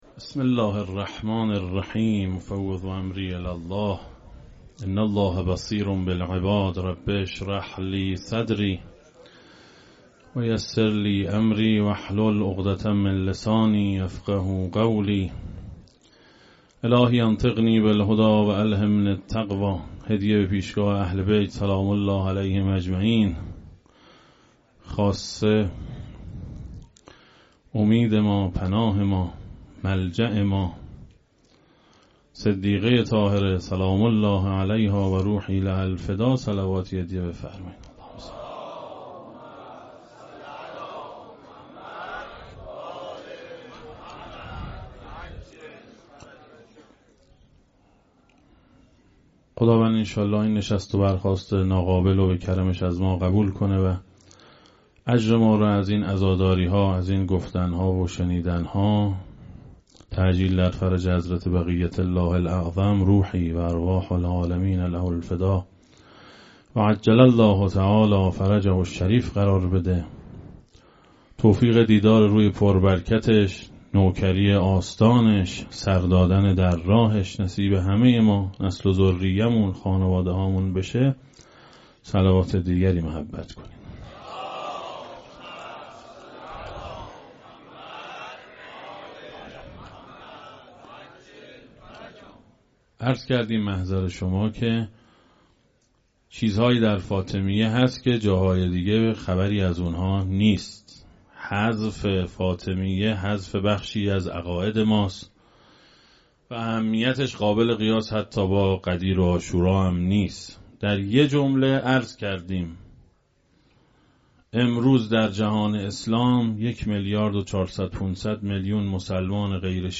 بیانات ارزشمند